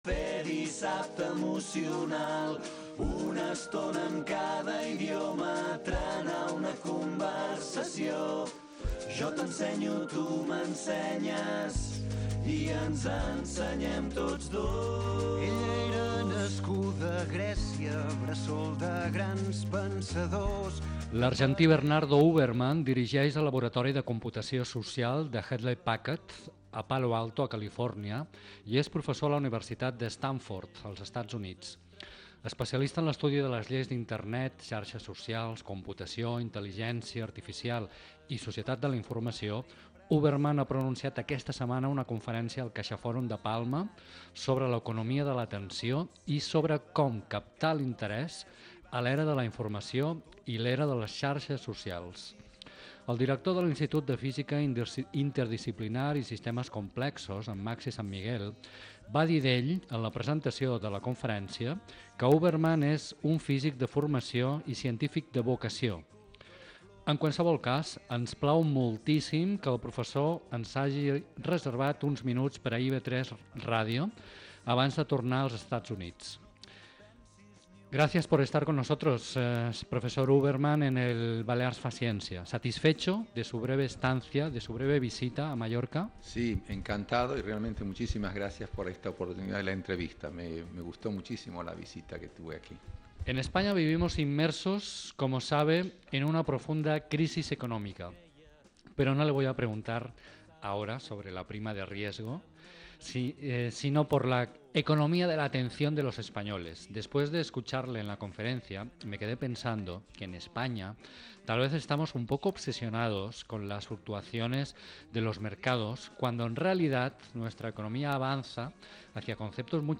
CONFERENCIA. LA ECONOMÍA DE LA ATENCIÓN: CÓMO CAPTAR EL INTERÉS EN LA ERA DE LA INFORMACIÓN Y LAS REDES SOCIALES